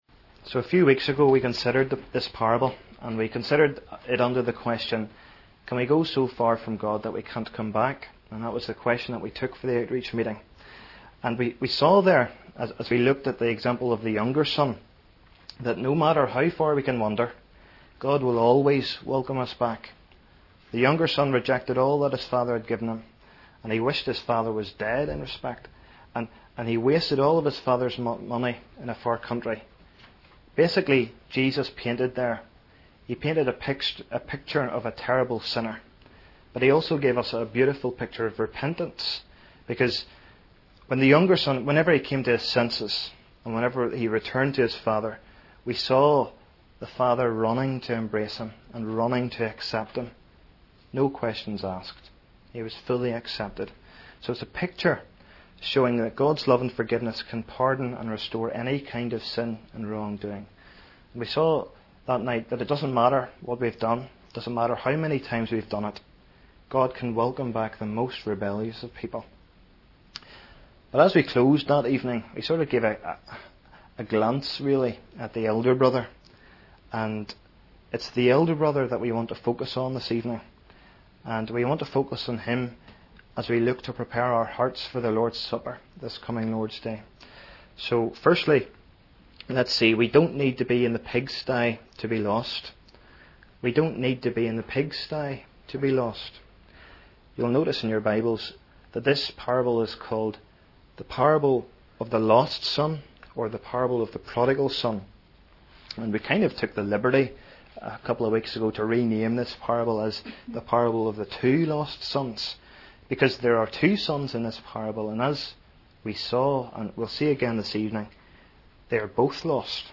sermons:Podcast 'Elder Brothers.mp3'